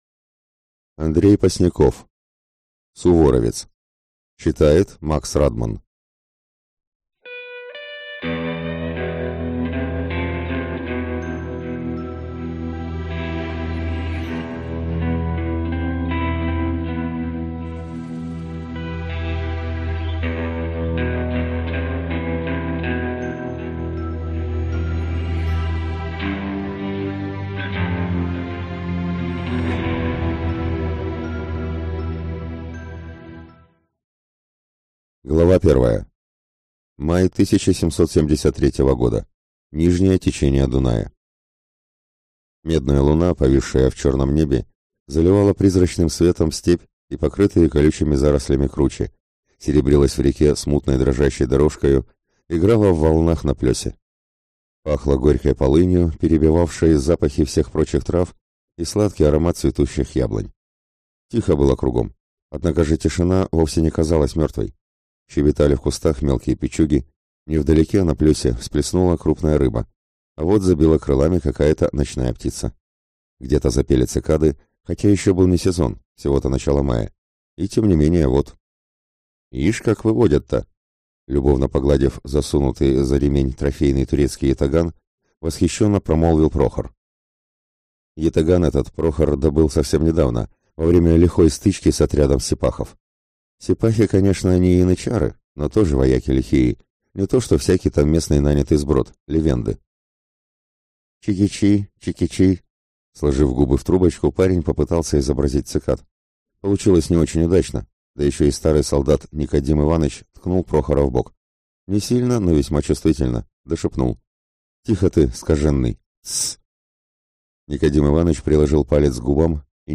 Аудиокнига Суворовец | Библиотека аудиокниг